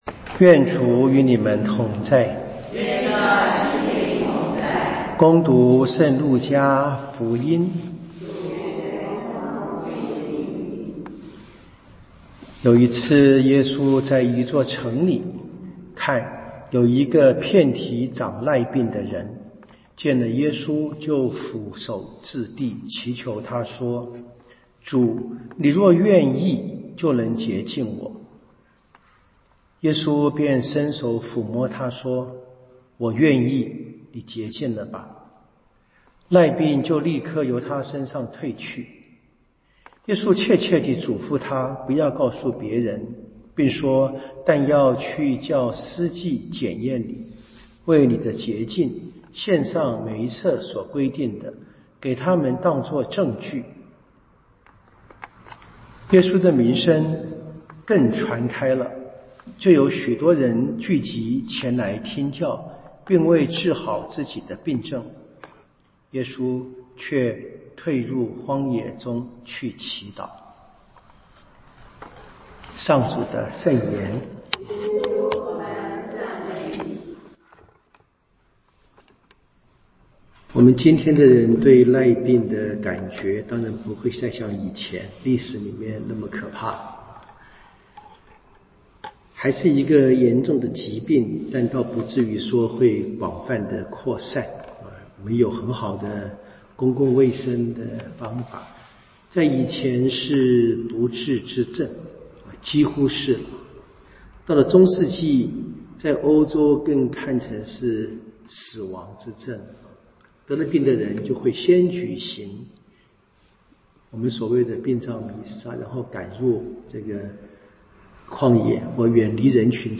2026年1-2月彌撒講道